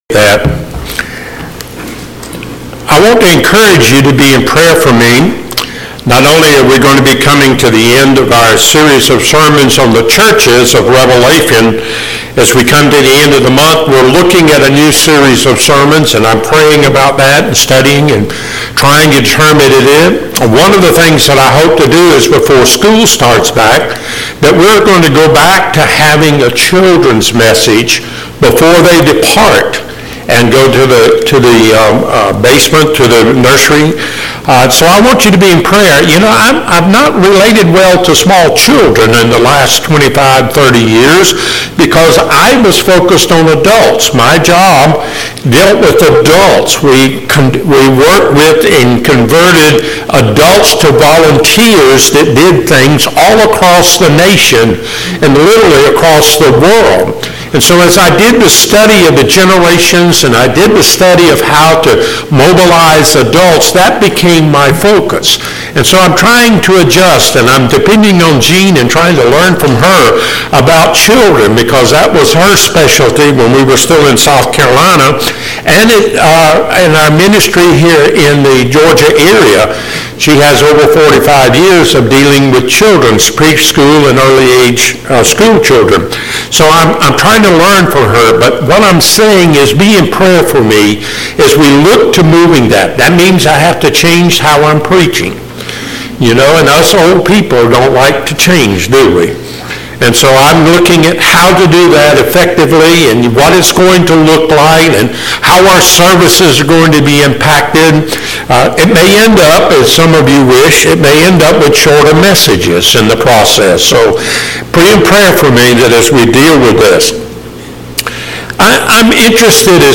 Passage: Revelation 3:1-6 Service Type: Sunday Morning